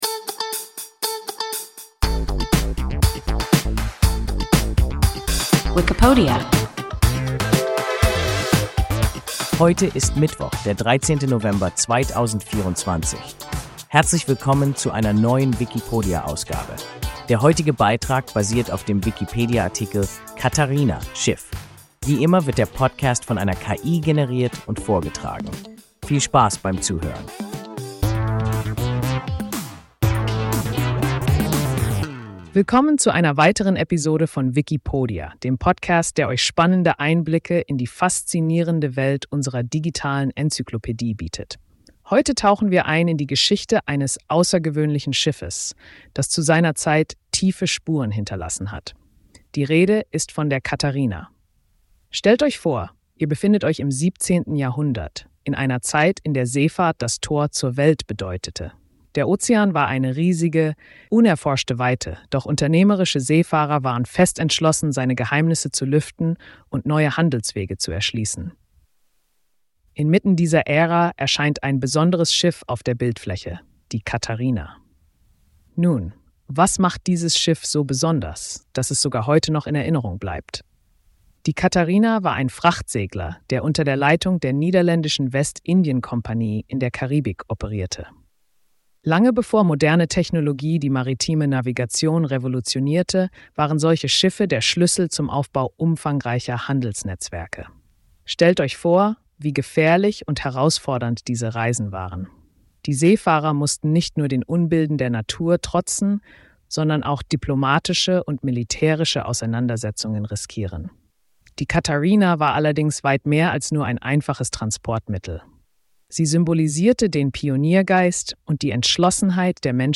Catarina (Schiff) – WIKIPODIA – ein KI Podcast